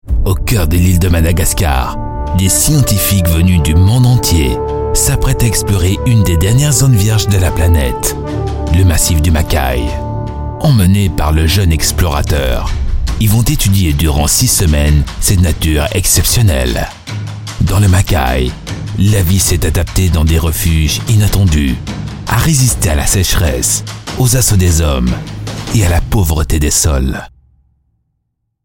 Dokumentarfilme
Micro SE Electronics und Beats Mixr